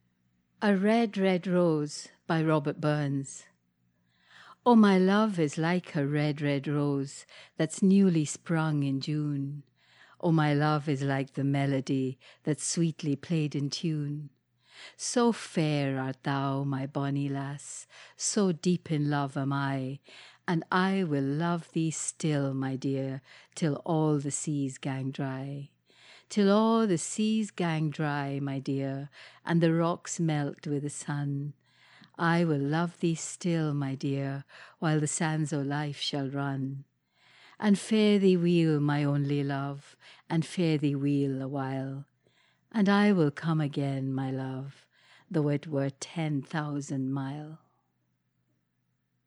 mtiaz Dharker reads a Red Red Rose by Robert Burns
Imtiaz-Dharker-reads-A-Red-Red-Rose-by-Robert-Burns.mp3